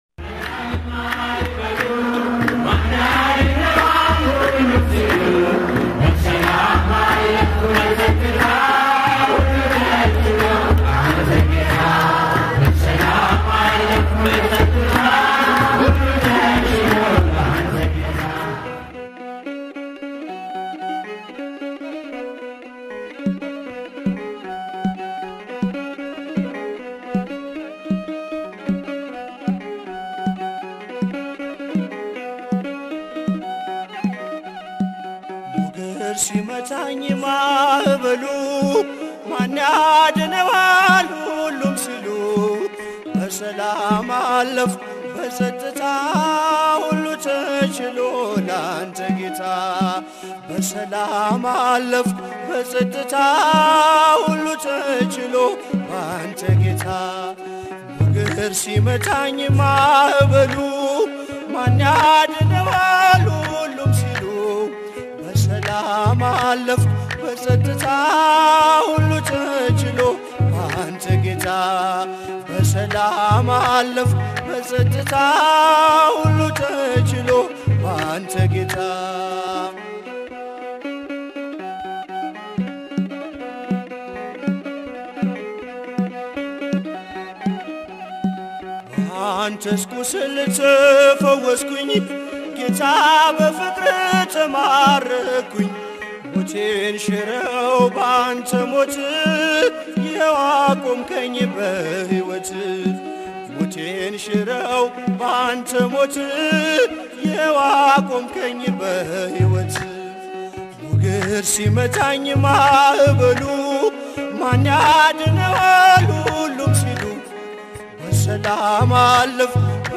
መዝሙር (ሞገድ ሲመታኝ ማዕበሉ) May 6, 2018